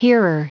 Prononciation du mot hearer en anglais (fichier audio)
Prononciation du mot : hearer